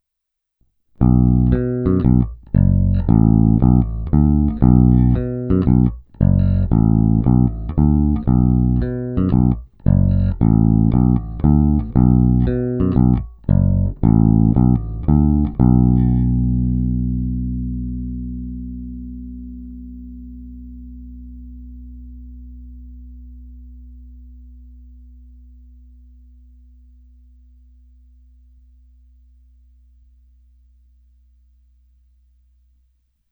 Není-li uvedeno jinak, následující nahrávky jsou provedeny rovnou do zvukové karty, jen normalizovány, jinak ponechány bez úprav. Hráno vždy nad aktivním snímačem, v případě obou pak mezi nimi.
Oba snímače